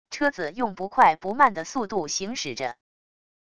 车子用不快不慢的速度行驶着wav音频生成系统WAV Audio Player